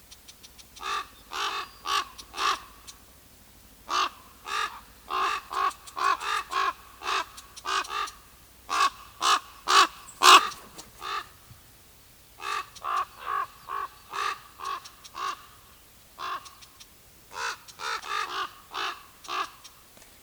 Holló (Corvus corax) hangja
A holló (Corvus corax) hangja a mély, rekedt „krah” vagy „kraa” hang, amely messziről is jól hallható.
A csendesebb, nyugodtabb helyeken a holló rekedtes rikácsolása különösen jól hallható és jellemző.
A holló (Corvus corax) hangja egy mély, rekedt, jellegzetes „krah” vagy „kraa” hang, amely az egyedek közti kommunikáció, a területvédelem, a társas kapcsolatok fenntartása és a veszélyre figyelmeztetés legfontosabb eszköze.